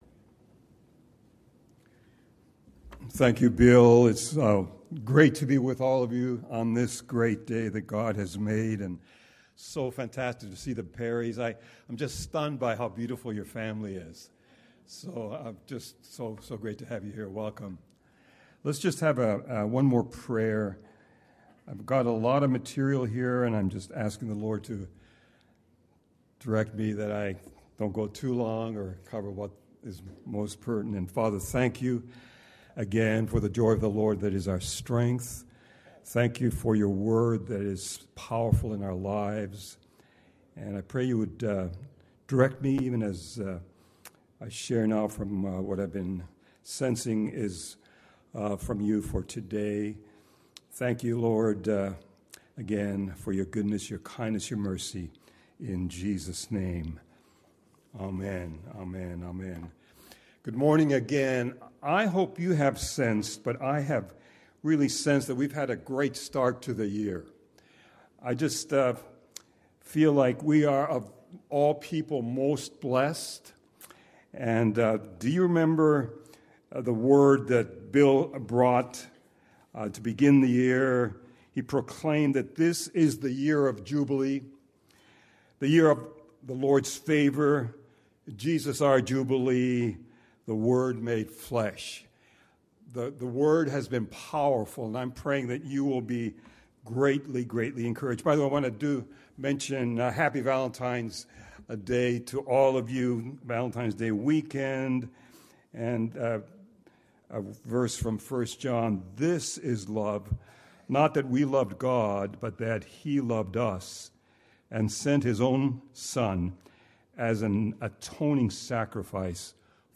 Tulsa Christian Fellowship Sermon Audio